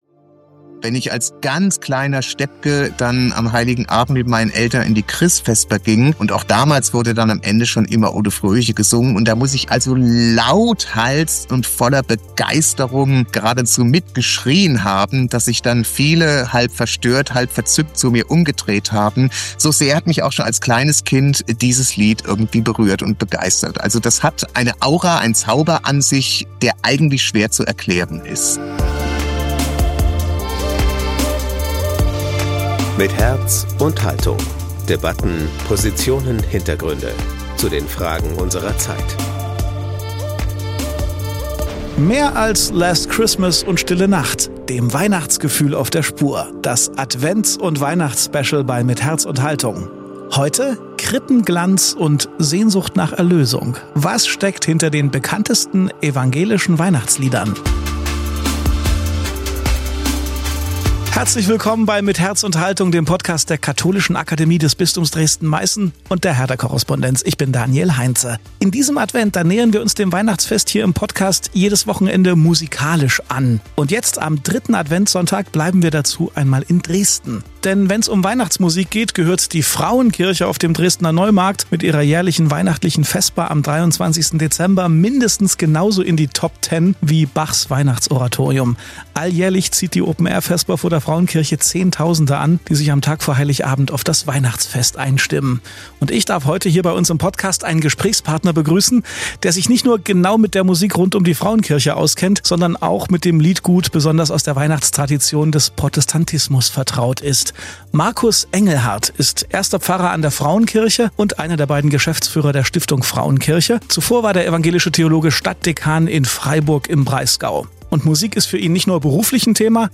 Am dritten Adventssonntag bleiben wir direkt in Dresden und dürfen einen Gesprächspartner begrüßen, der sich nicht nur mit der Musik rund um die Frauenkirche genau auskennt, sondern auch mit dem Liedgut besonders aus der Weihnachtstradition des Protestantismus vertraut ist.